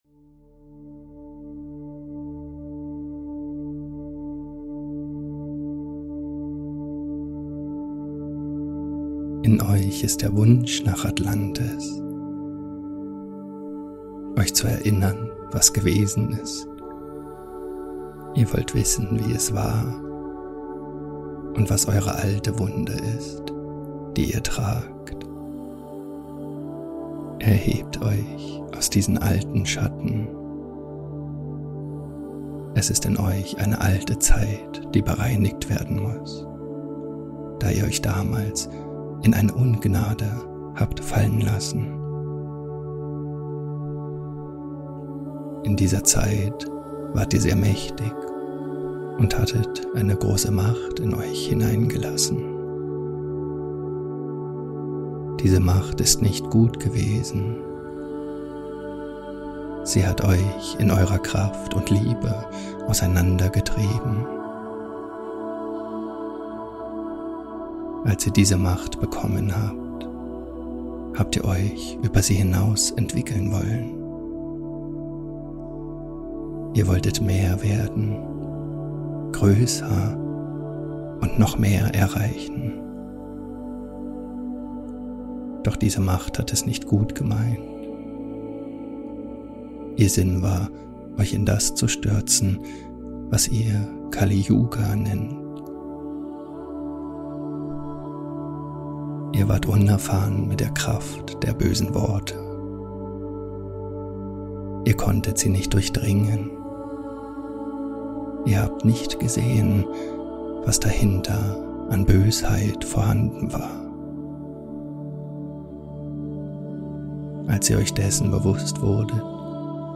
Eine Heil-Meditation zum Lösen des individuellen Atlantis-Karmas